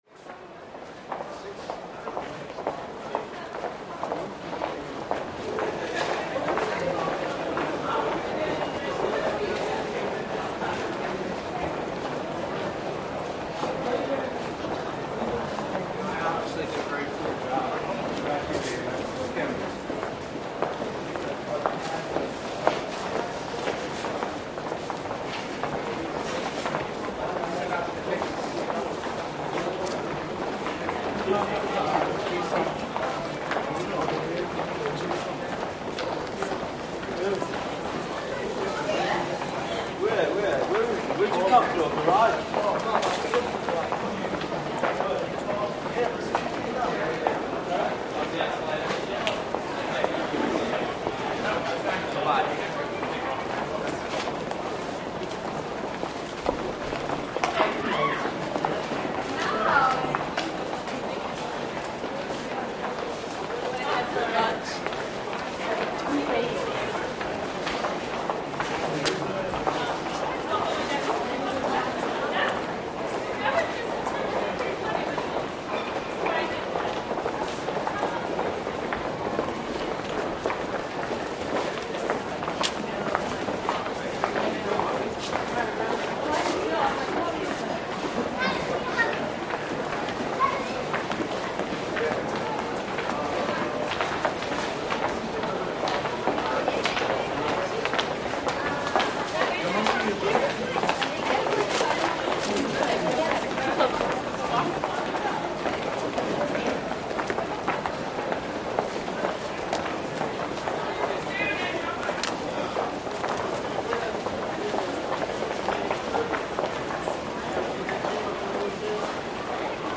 CROWD - INT VARIOUS GROUP FOOTSTEPS: INT: Crowd at rush hour, grand central station, low level drone of station, voices in Background.